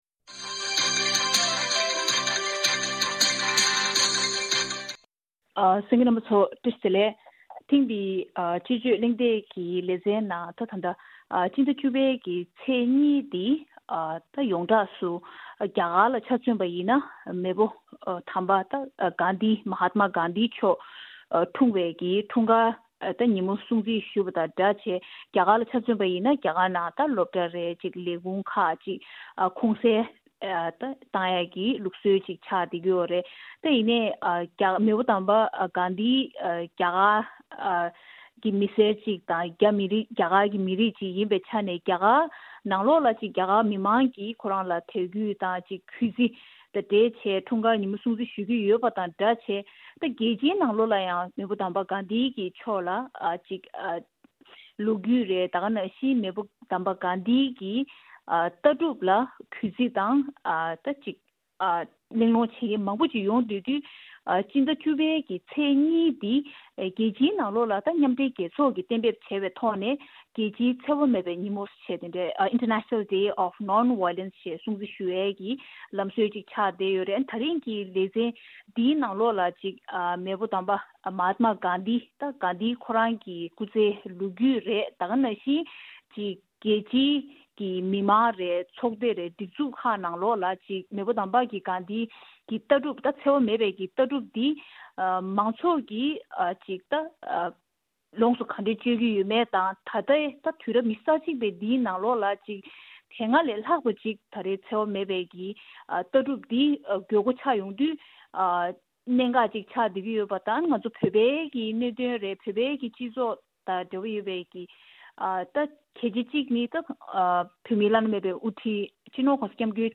ཐེངས་འདིའི་དཔྱད་བརྗོད་གླེང་སྟེགས་ཀྱི་ལས་རིམ་ནང་།